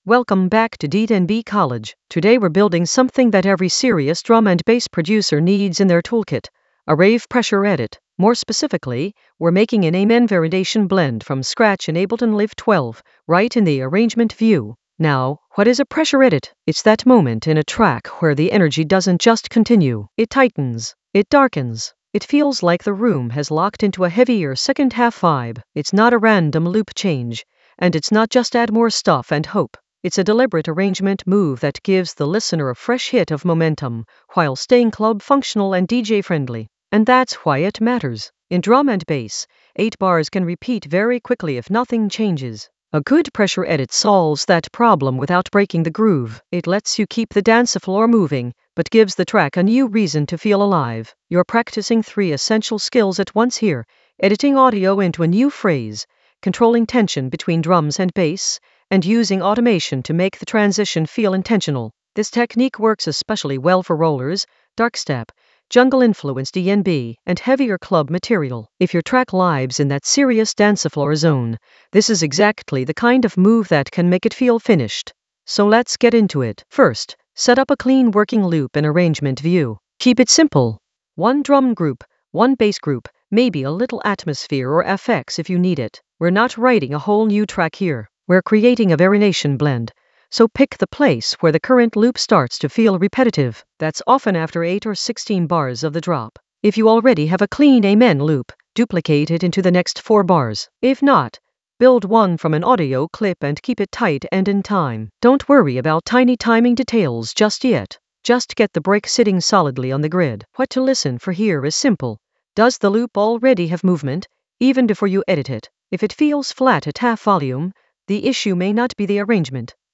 Narrated lesson audio
The voice track includes the tutorial plus extra teacher commentary.
An AI-generated beginner Ableton lesson focused on Rave Pressure edit: an amen variation blend from scratch in Ableton Live 12 in the Arrangement area of drum and bass production.